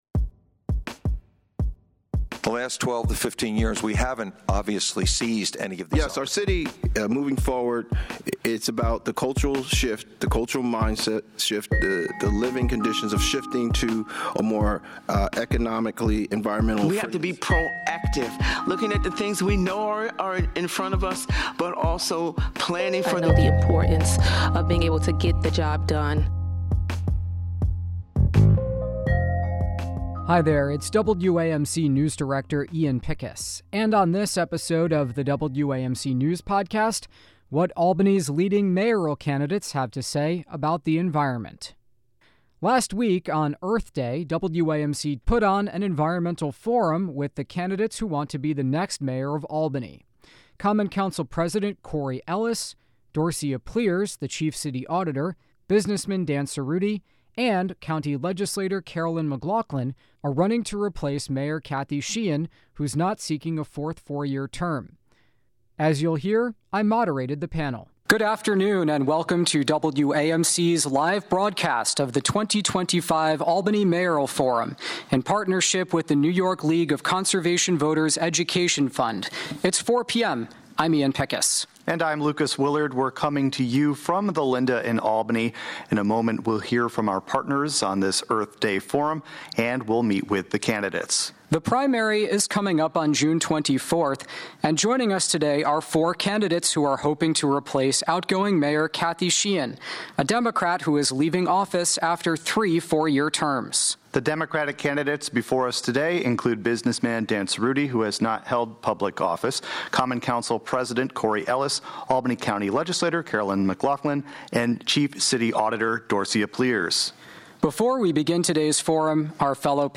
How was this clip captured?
The Democratic candidates for mayor of Albany in June 24's primary gather for an environmental forum hosted by WAMC.